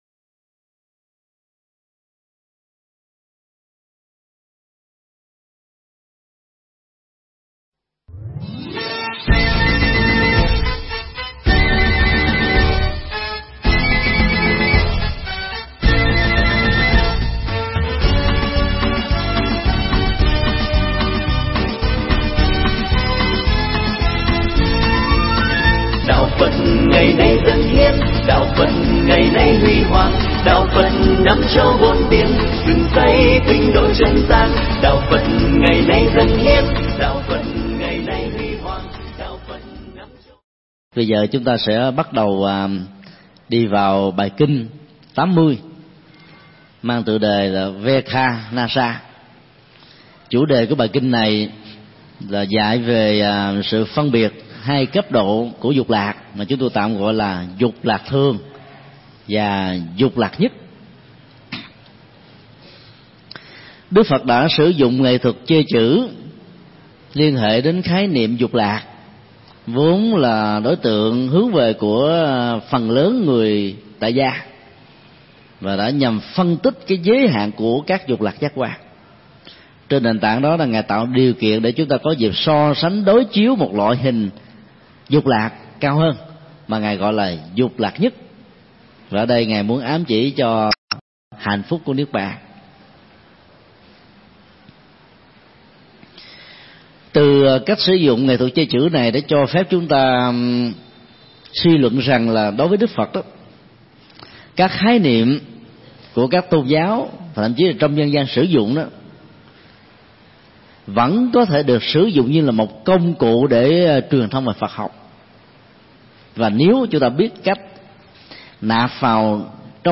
Mp3 Thuyết Pháp Kinh Trung Bộ 80 (Kinh Vekhanassa) – Dục lạc thường và dục lạc nhất
Giảng tại Chùa Xá Lợi, ngày 14 tháng 10 năm 2007